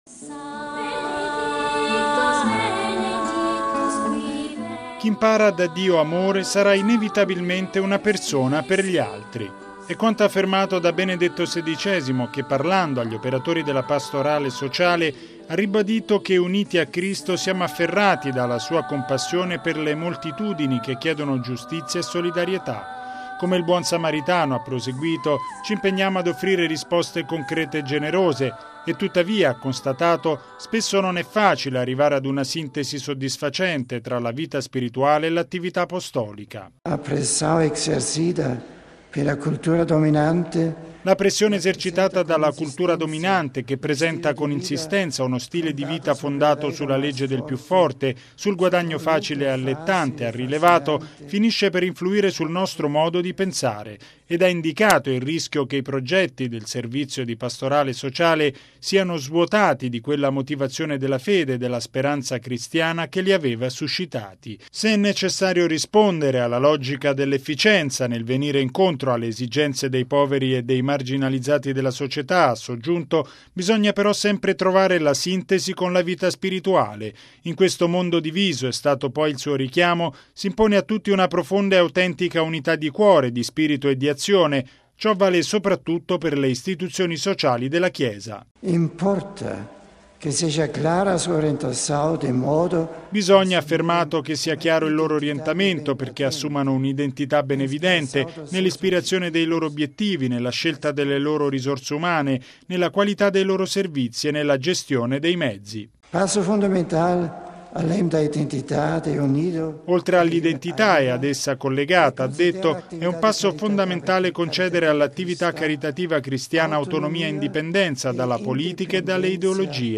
Canto
Applausi